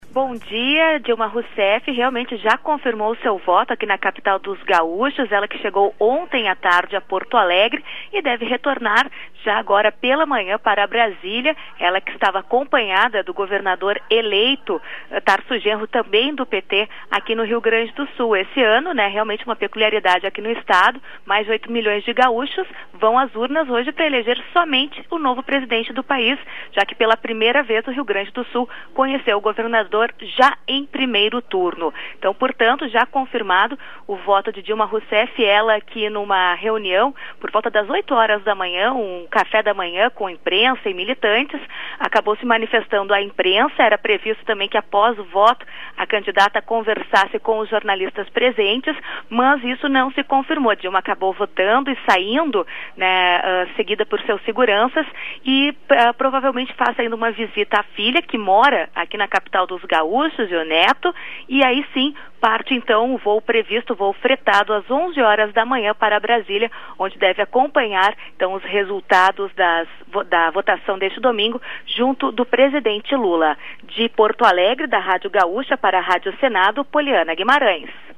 Flash da Rádio Gaúcha.